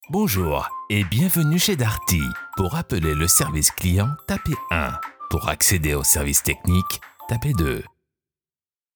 Male
Assured, Authoritative, Character, Confident, Cool, Corporate, Deep, Engaging, Natural, Reassuring, Smooth, Soft, Warm, Versatile, Young
Microphone: se electronics x1s